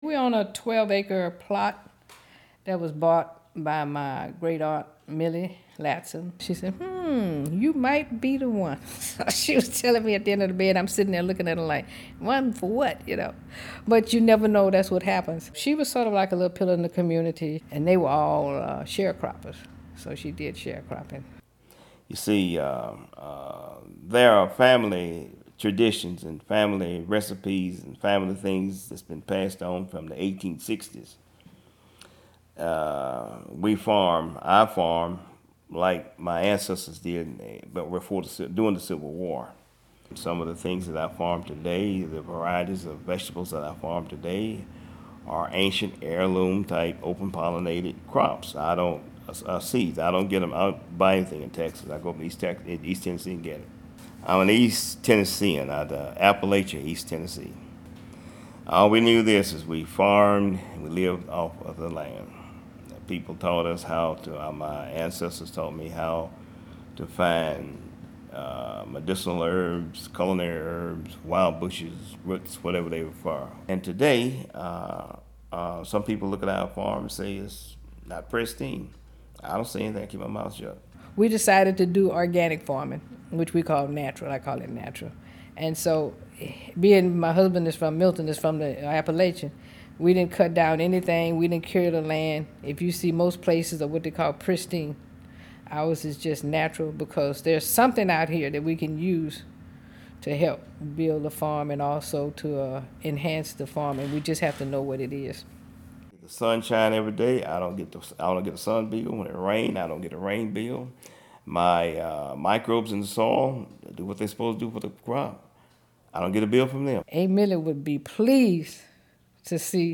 interview except